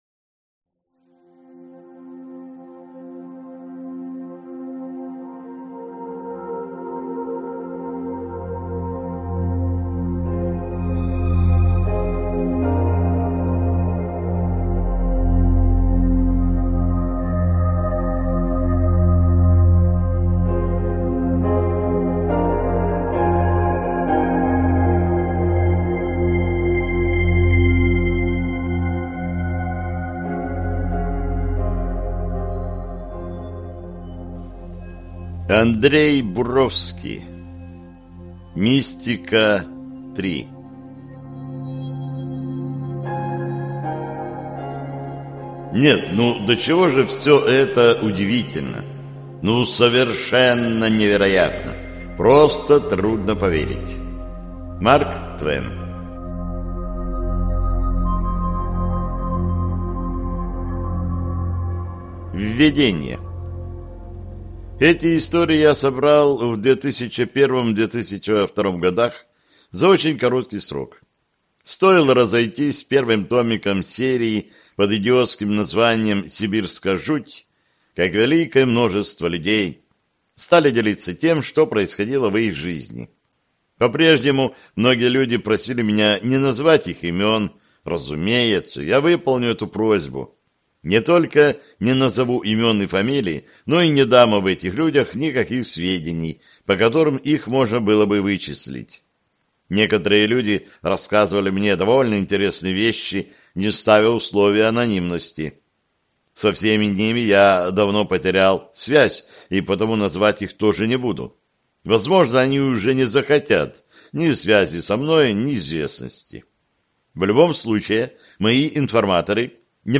Аудиокнига Удивительные истории из разных мест. Книга 2 | Библиотека аудиокниг